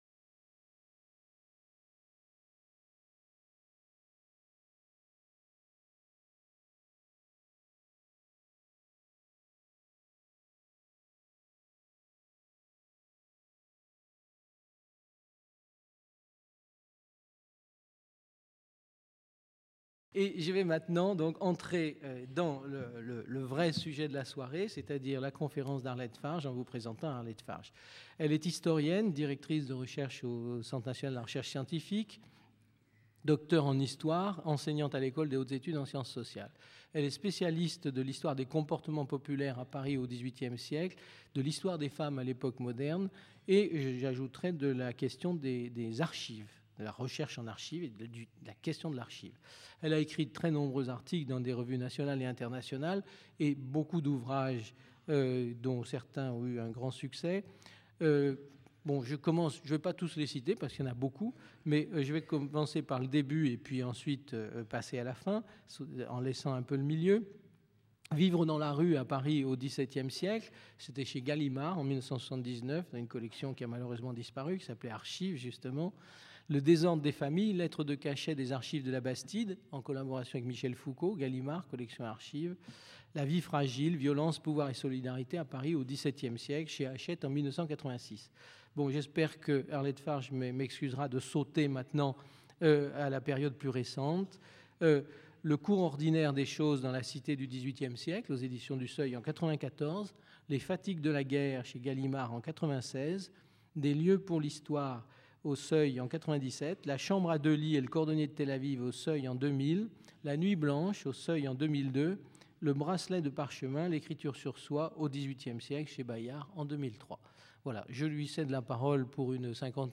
Une conférence de l'UTLS Avec Arlette Farge (docteur en histoire moderne)